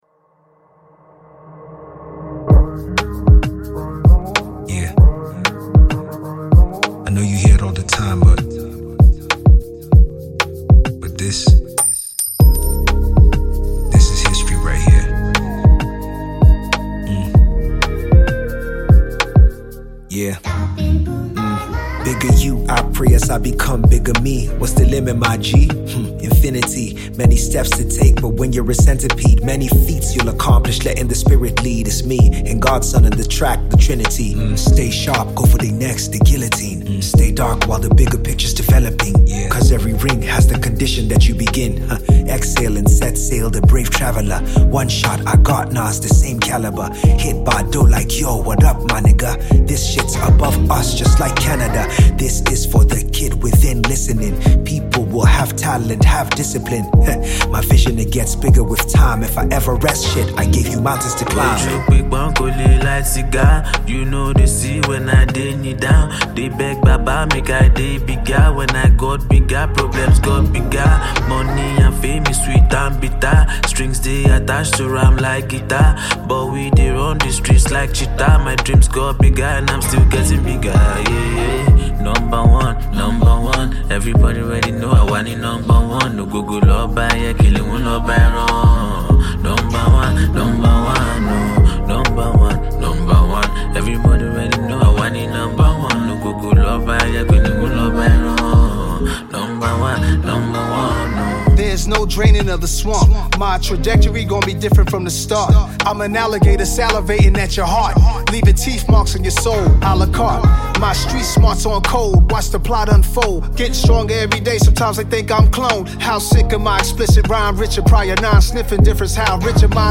Popular Nigerian Rap Legend